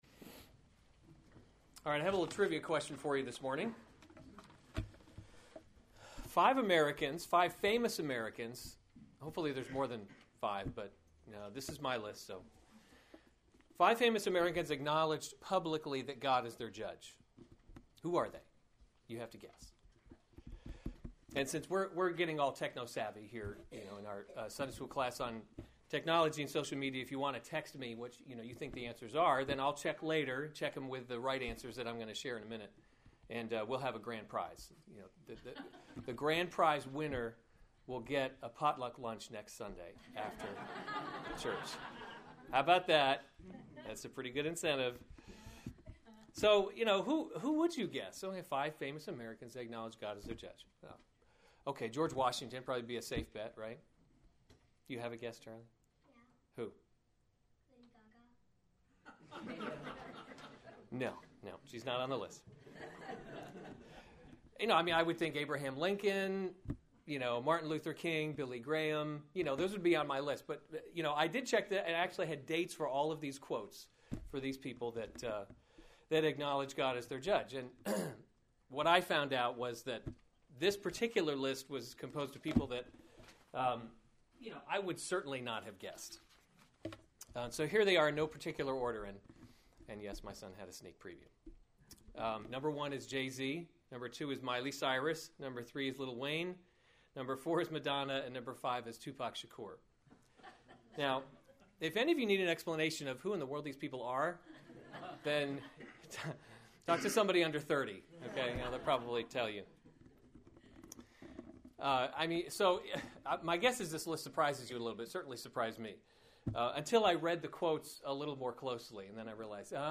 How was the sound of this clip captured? April 11, 2015 Romans – God’s Glory in Salvation series Weekly Sunday Service Save/Download this sermon Romans 14:1-12 Other sermons from Romans Do Not Pass Judgment on One Another 14:1 As […]